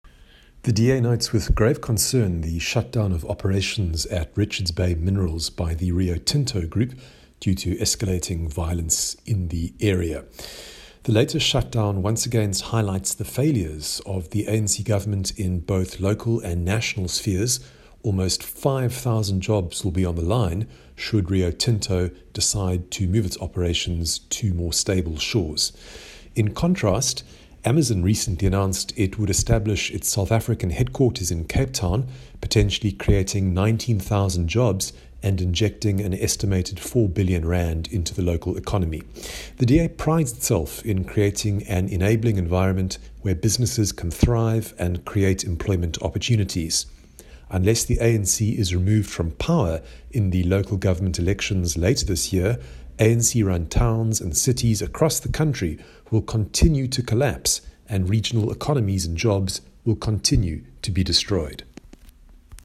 soundbite by Dr Michael Cardo MP.